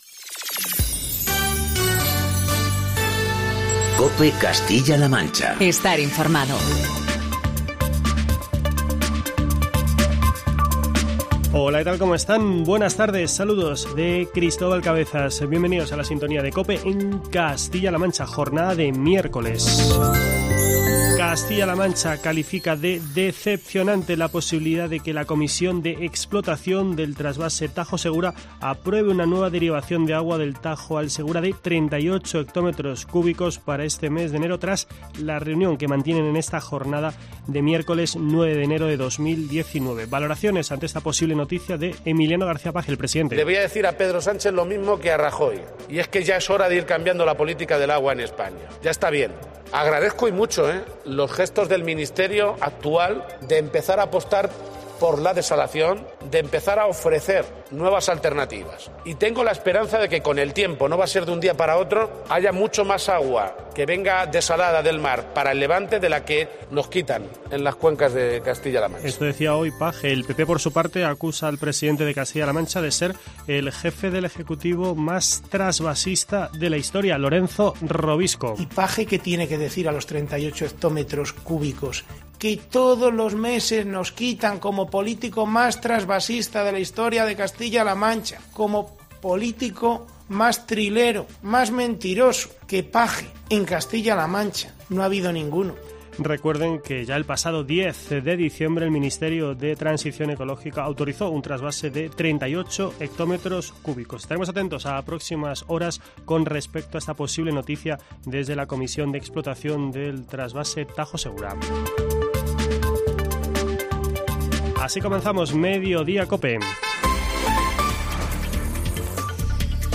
El presidente de la Sala de lo Penal del Tribunal Supremo Manuel Marchena ha sido el encargado este año de impartir la lección inaugural